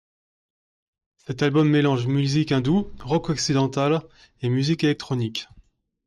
Read more (relational) of the West; Western western Frequency C2 Pronounced as (IPA) /ɔk.si.dɑ̃.tal/ Etymology Borrowed from Latin occidentālis In summary Borrowed from Latin occidentālis.